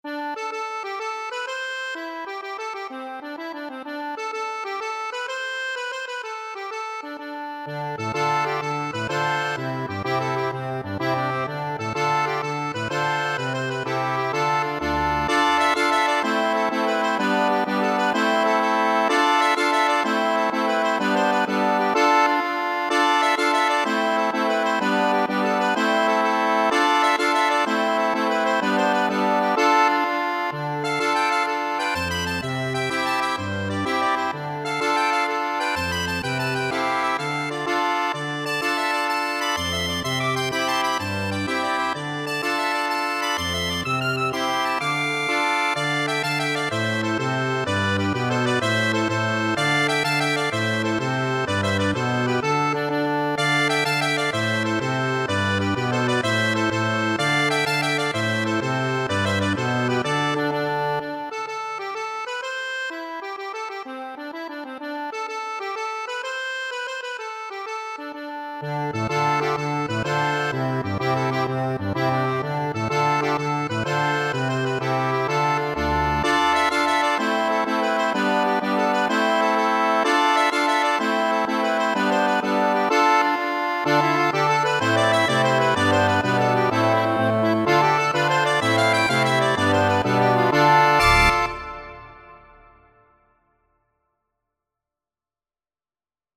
Fast .=c.126
12/8 (View more 12/8 Music)
Accordion  (View more Intermediate Accordion Music)
Irish